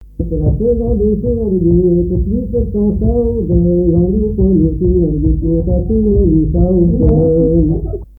Mémoires et Patrimoines vivants - RaddO est une base de données d'archives iconographiques et sonores.
Divertissements d'adultes - Couplets à danser
Répertoire à l'accordéon diatonique
Pièce musicale inédite